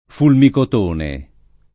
fulmicotone [ fulmikot 1 ne ] s. m.